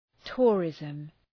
Προφορά
{‘tʋrızm}